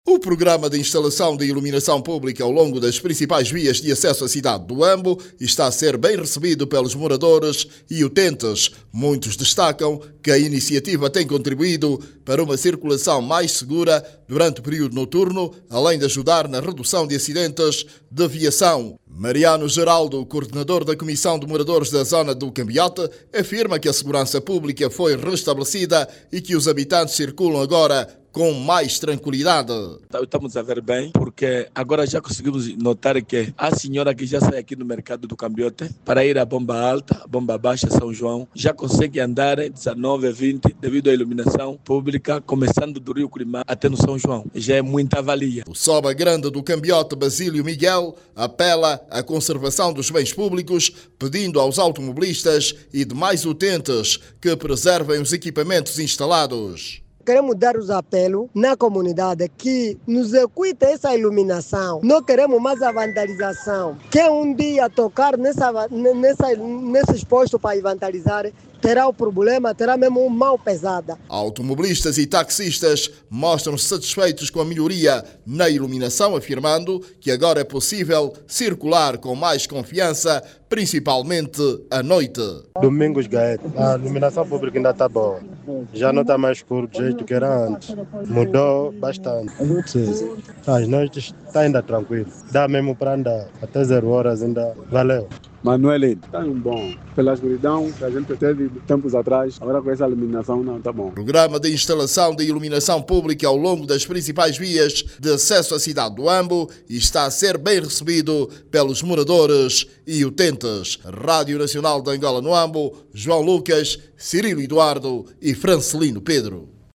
A população do Huambo, reconhece melhorias na iluminação pública, no quadro de um projecto implementado na região. Os habitantes da cidade do Planalto Central, afirmam que com este avanço, já se pode circular em segurança. Clique no áudio abaixo e ouça a reportagem